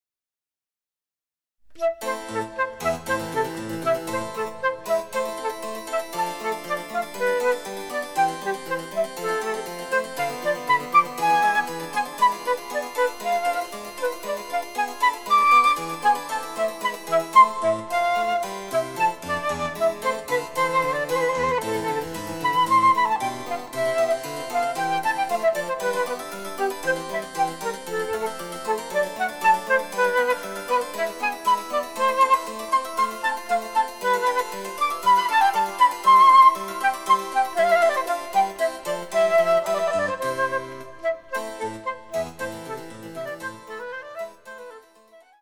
■フルートによる演奏
チェンバロ（電子楽器）